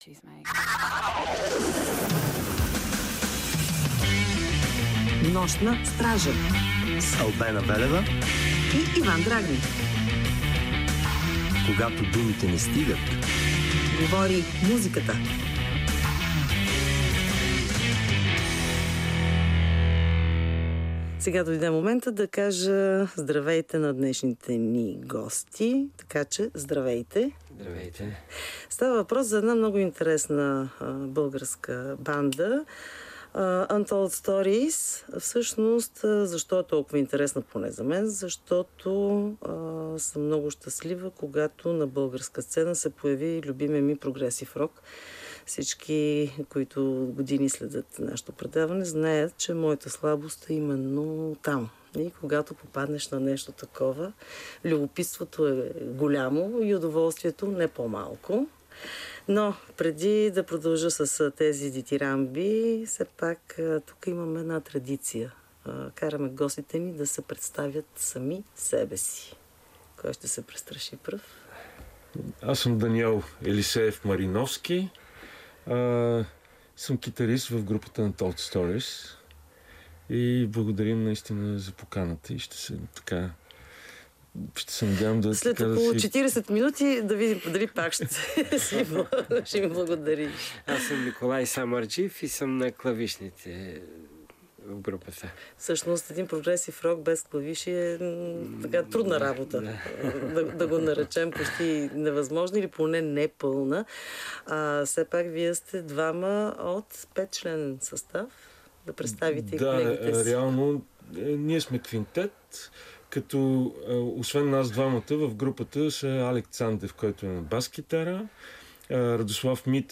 В поредното издание на Нощна стража гостува прогресив рок група UNTOLD STORIES
Музикантите описват стила си като арт рок с елементи на мелодичен рок, тежък прог и космически рок.